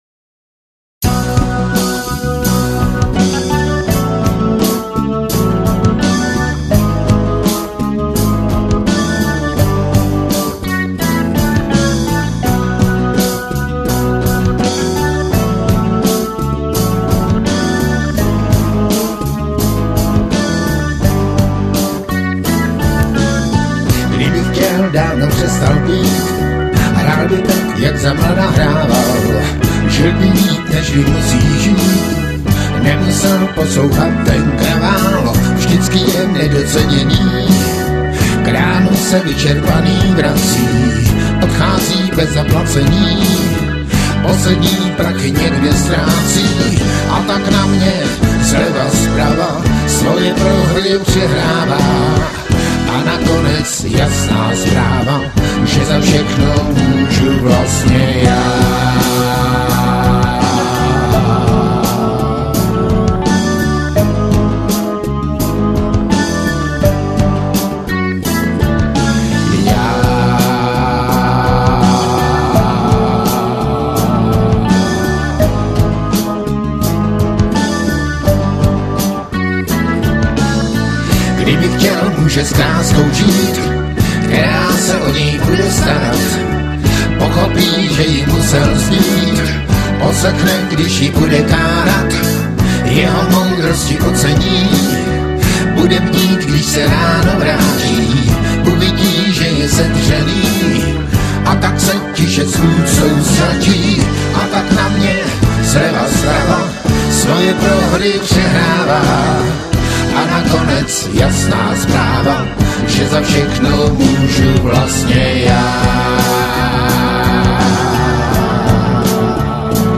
POHODA rock
Bicí
Klávesy
Sólova kytara